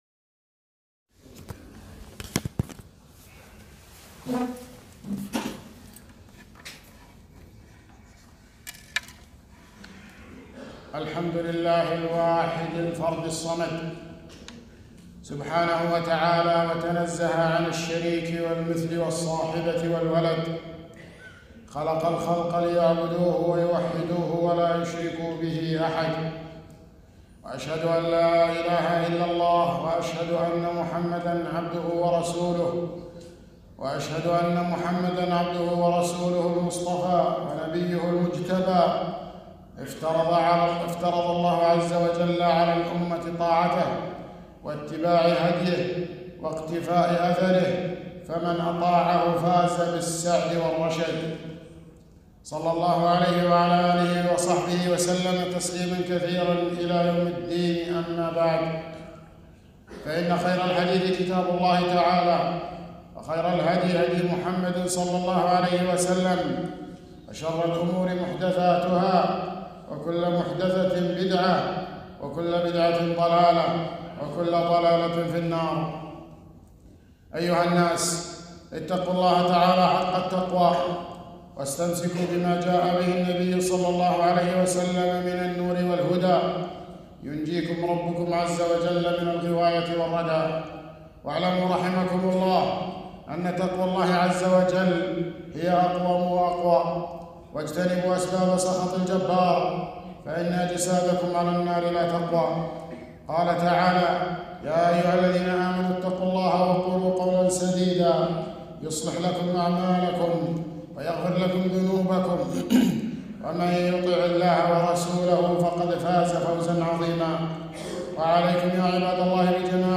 خطبة - محبة النبي بين أهل الحق و أهل الغلو